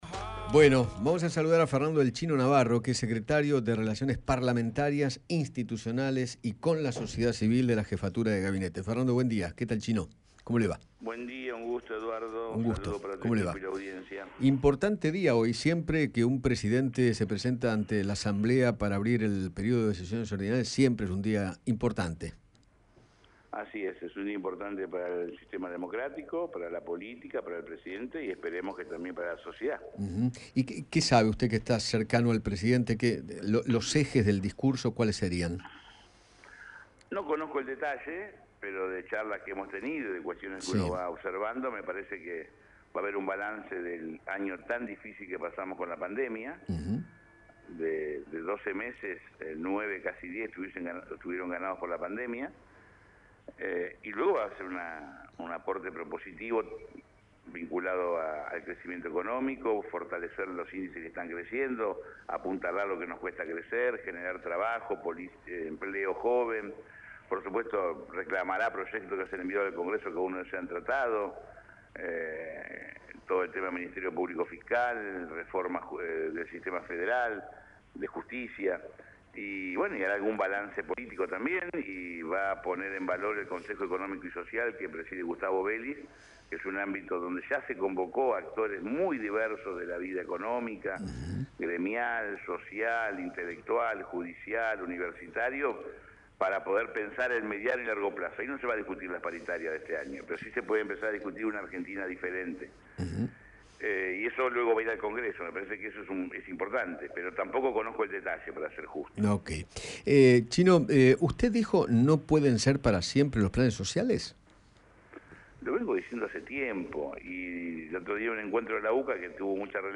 Fernando ‘chino’ Navarro, secretario de Relaciones Parlamentarias, Institucionales y con la Sociedad Civil de la Jefatura de Gabinete, dialogó con Eduardo Feinmann acerca de la marcha del sábado contra el Gobierno y se refirió al discurso que dará Alberto Fernández en la apertura de las sesiones extraordinarias.